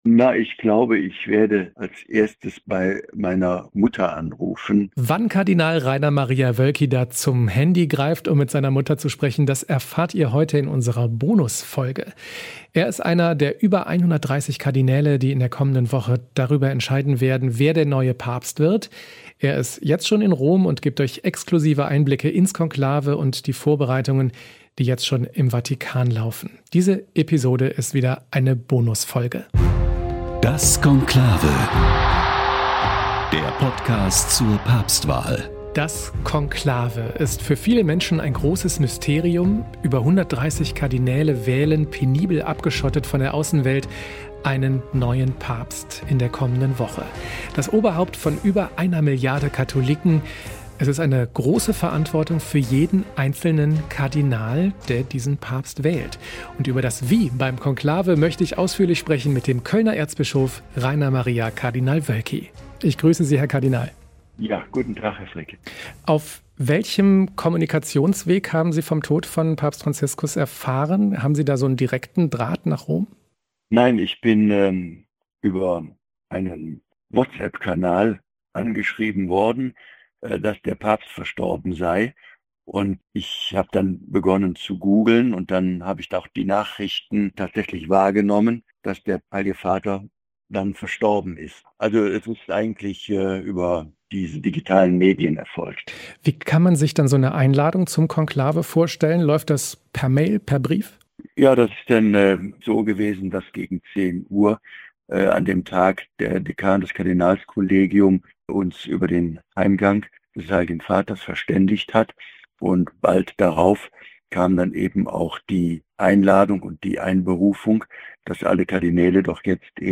Der Kölner Erzbischof Rainer Kardinal Woelki ist einer von nur drei deutschen Kardinälen, die im anstehenden Konklave den künftigen Papst wählen dürfen. Hier spricht er im exklusiven Interview mit dem Konklave-Podcast!